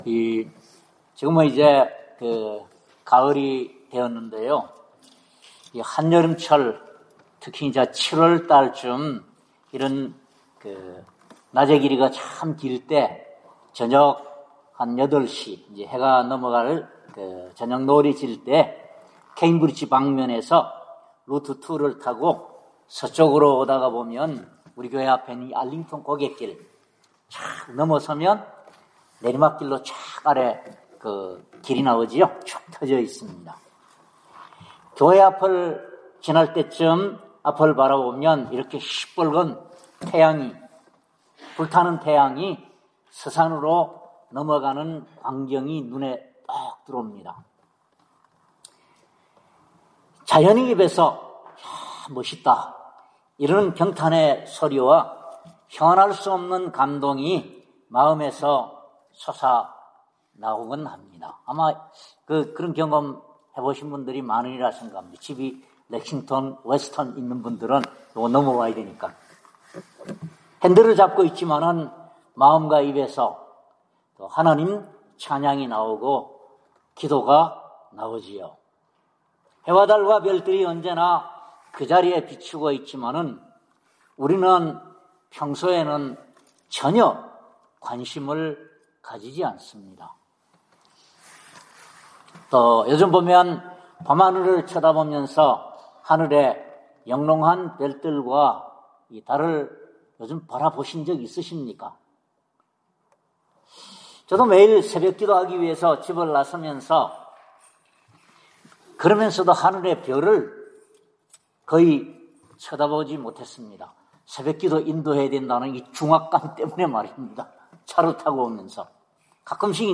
62장:5절 Service Type: 주일예배 첫째로